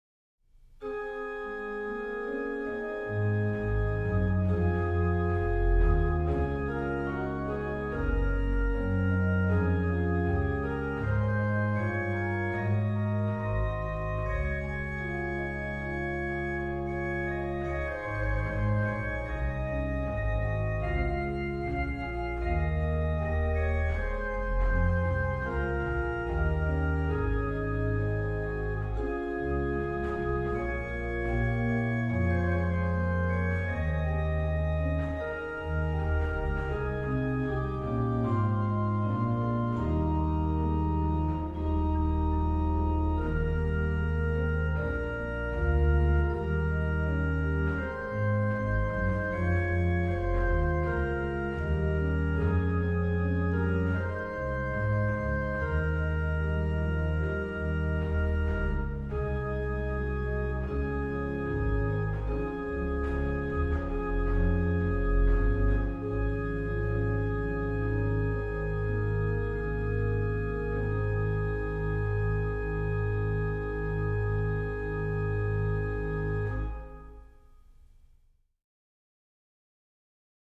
Subtitle   à 2 Clav. et Ped.
Venue   1717 Trost organ, St. Walpurgis, Großengottern, Germany
Registration   rh: MAN: Bor8, Gems4, Nas3
lh: POS: Lged8, Fl4
PED: Sub16, Bor8